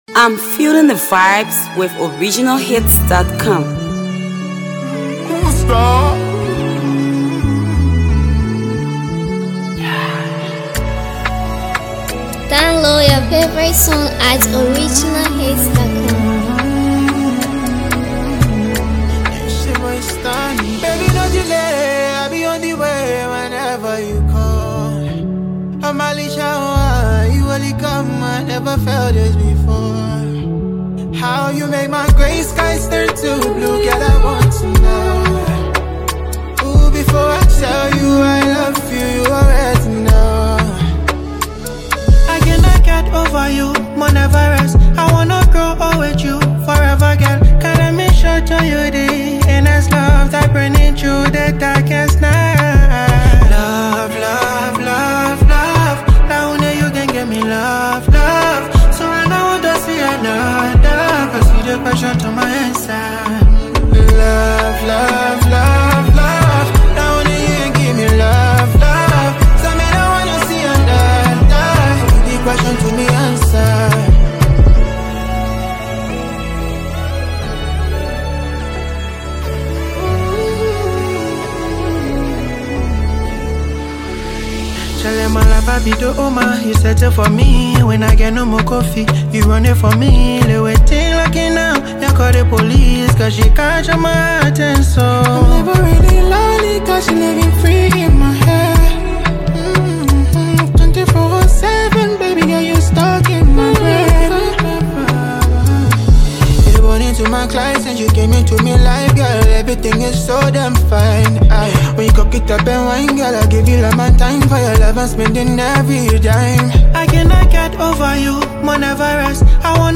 Sensational vocalist
curated melodic offering